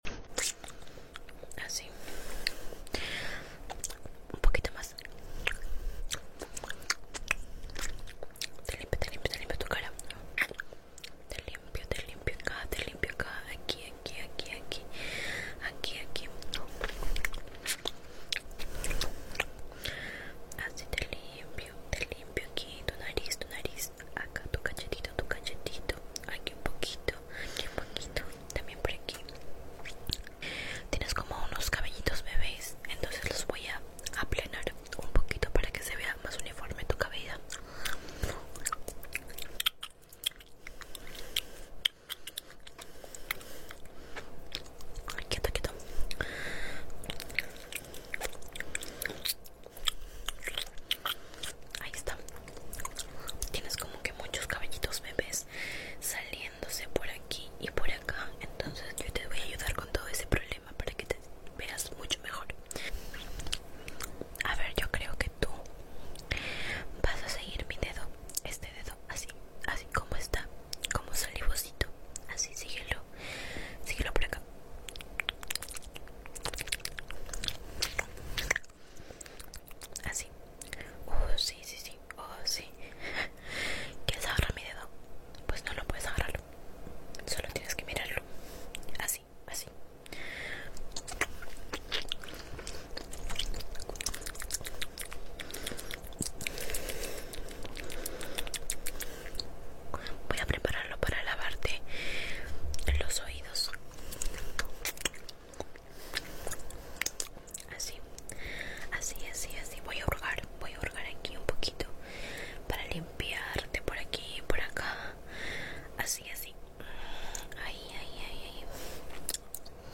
Asmr | Spit Painting 👄 Sound Effects Free Download